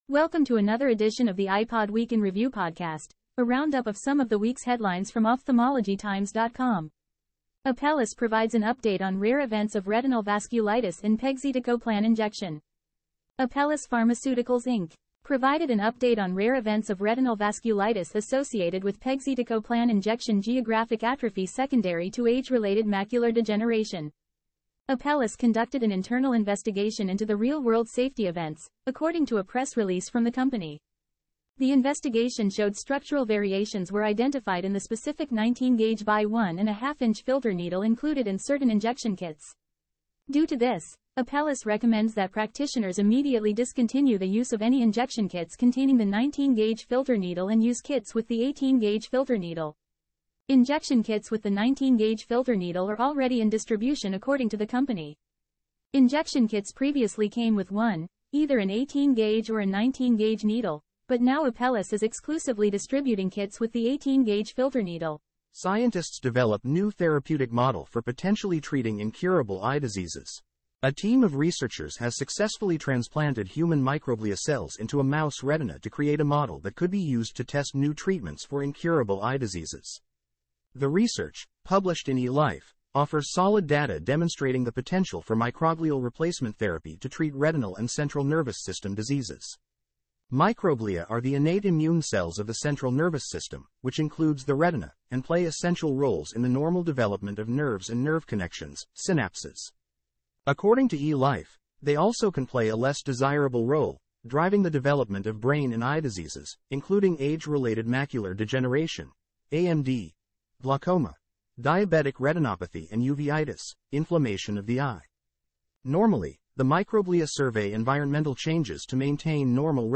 Editor's Note: This podcast was generated from Ophthalmology Times content using an AI platform.